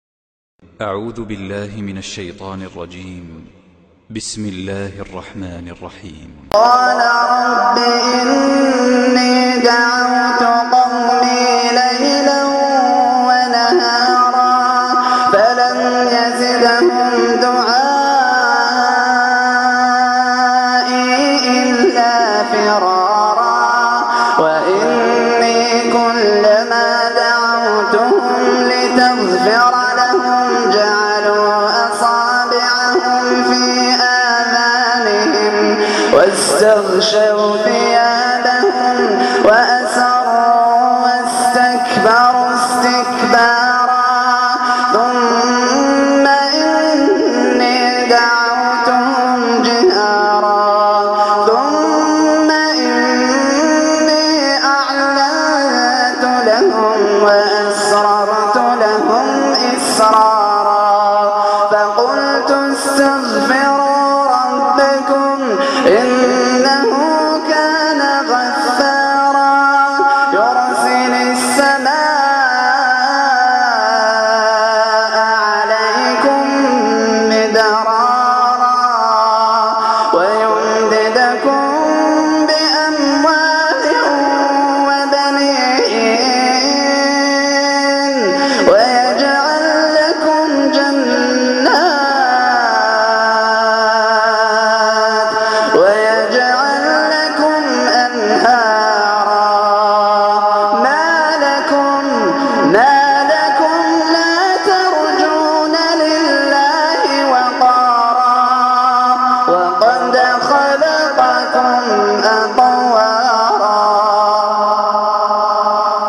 تلاوة القران بصوت خاشع